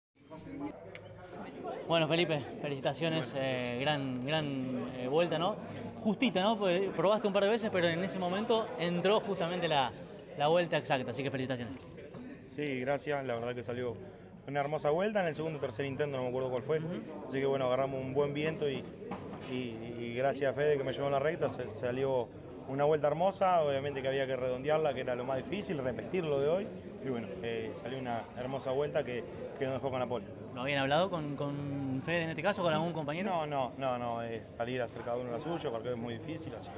en diálogo con CÓRDOBA COMPETICIÓN tras lograr el mejor tiempo clasificatorio